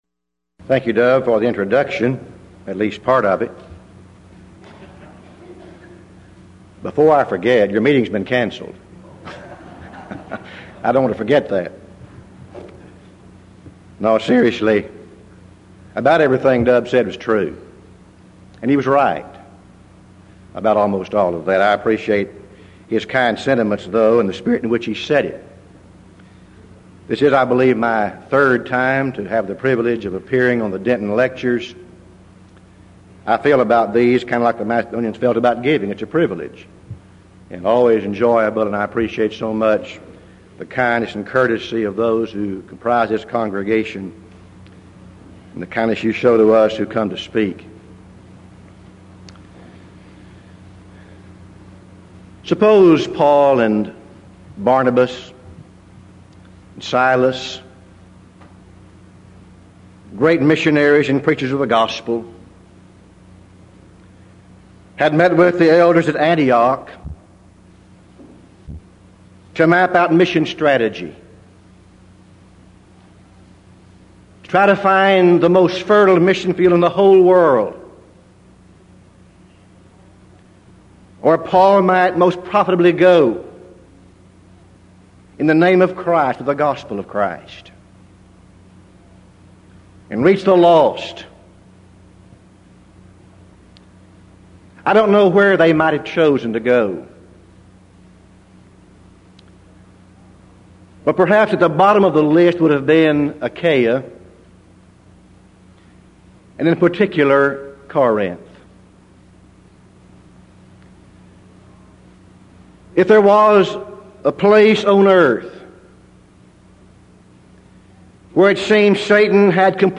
Event: 1989 Denton Lectures
this lecture